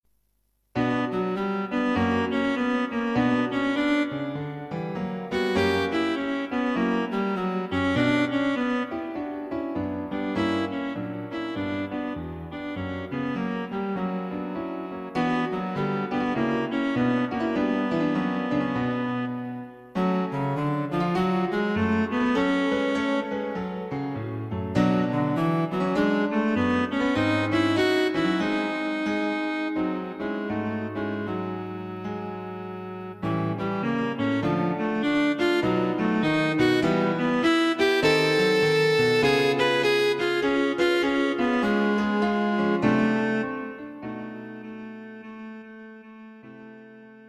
A bright, modern, light piece for solo Cello and Piano.
Combining a swing rhythm with interesting harmonies.